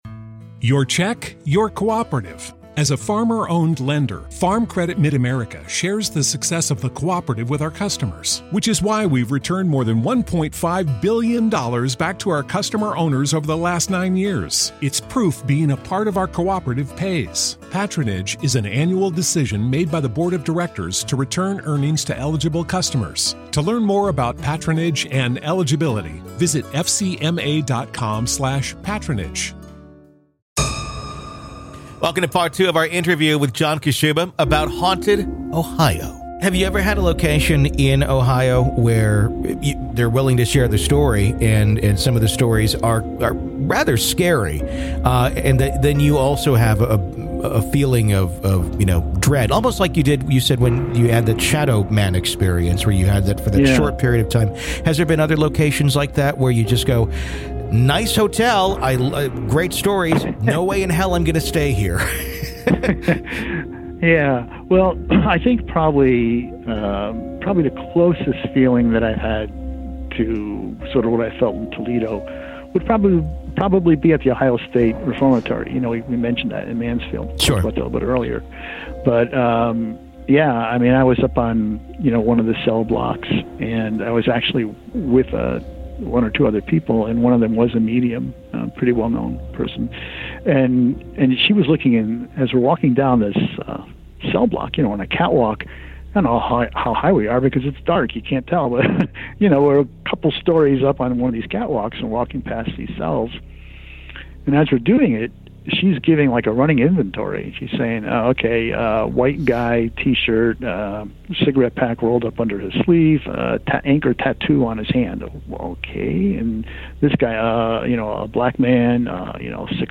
Why do some spirits remain in these rusting towns, long after the living have moved on? What draws the dead to places of former glory and current decay? This is Part Two of our conversation.